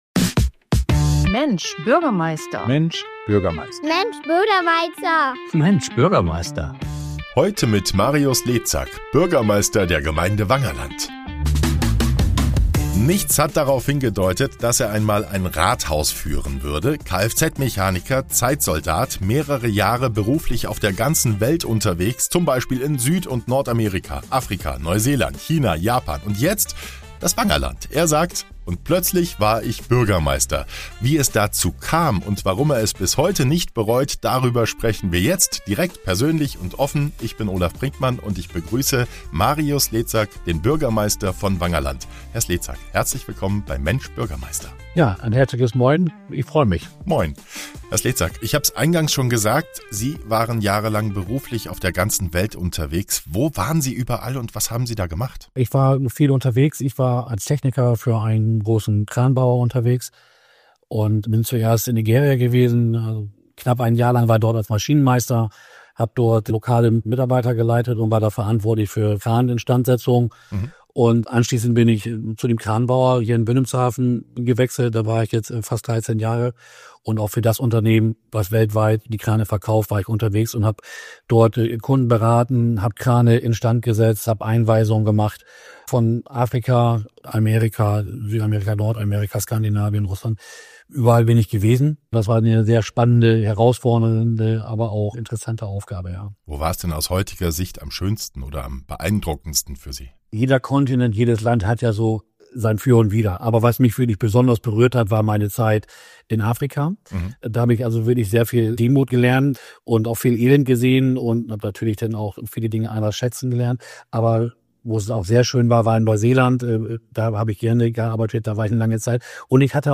In dieser Episode von "Mensch Bürgermeister!" ist der Bürgermeister von Wangerland zu Gast – ein Mann, der vor seinem Amtsantritt die ganze Welt gesehen hat...